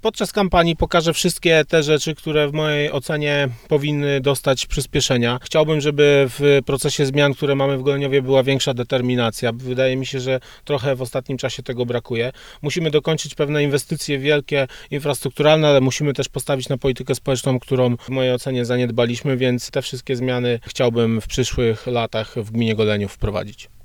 Zamiar podjęcia wyzwania w wyścigu o burmistrzowski fotel ogłosił na Facebooku przewodniczący Rady Miejskiej w Goleniowie Łukasz Mituła. Ten związany do niedawna z Platformą Obywatelską polityk w wyborach chce wystartować z własnym komitetem, a jak sam nam powiedział decyzję o kandydowaniu podjął, bo zależy mu na zmianach, które jego zdaniem muszą w gminie nastąpić.